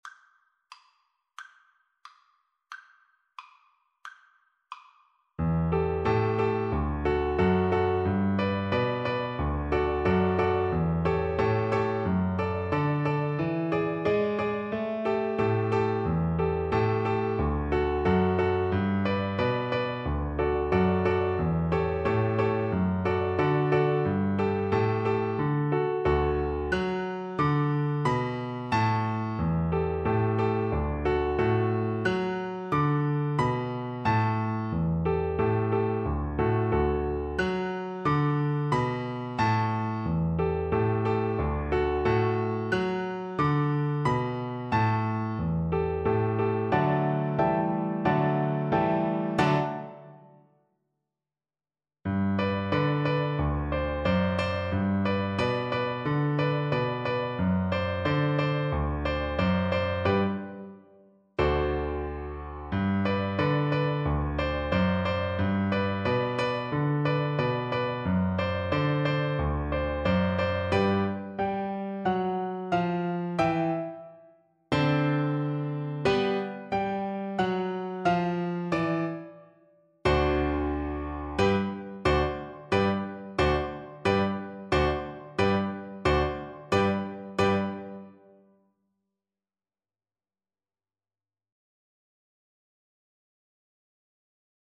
Allegro = 120 (View more music marked Allegro)
Classical (View more Classical Viola Music)